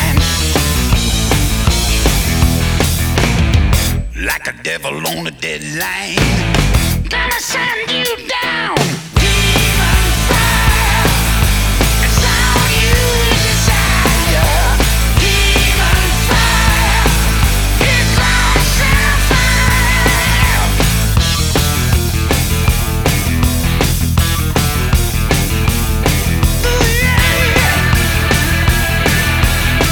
• Hard Rock